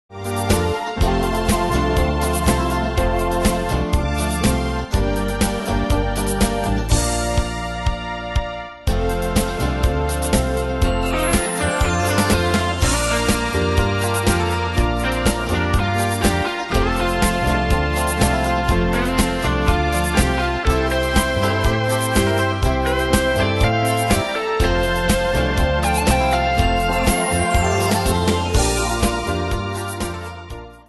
Style: Dance Ane/Year: 2002 Tempo: 122 Durée/Time: 4.21
Danse/Dance: Continental Cat Id.
Pro Backing Tracks